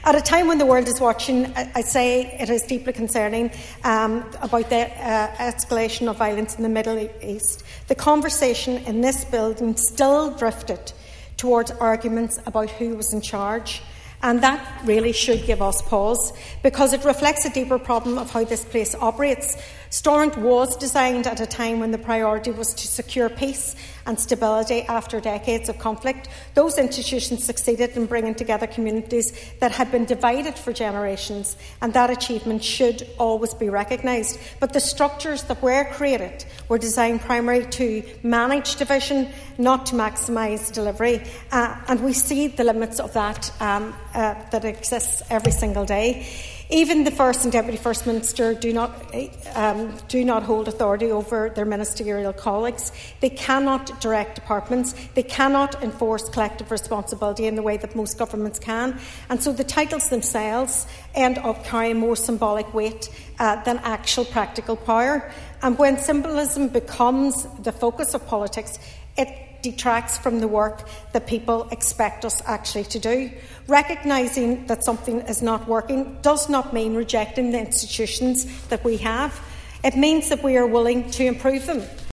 During a debate on renaming the titles of First and Deputy First Minister, one Foyle MLA has spoken in favour of a change.
Foyle MLA Sinead McLaughlin, highlights how the name of the department is the only authority of the office: